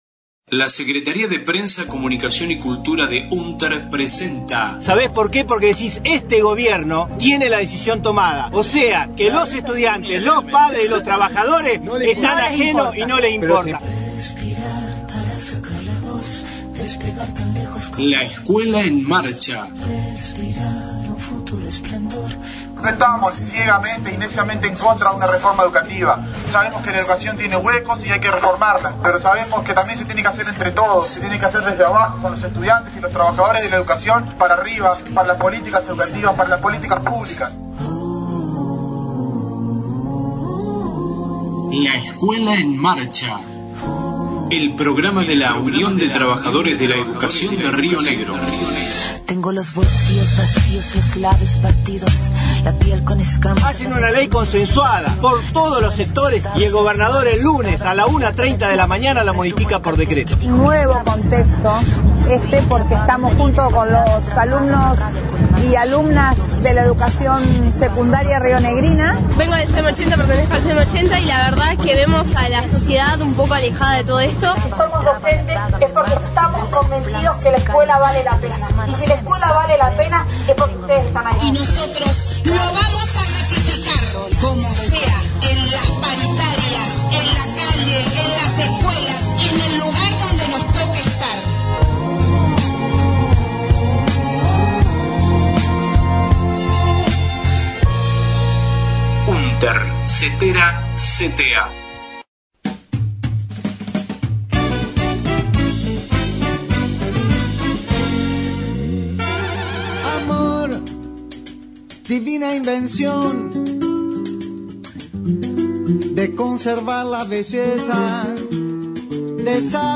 Audio Congreso asunción conducción periodo 2019 – 2022, Roca – Fiske Menuco, 29/11/19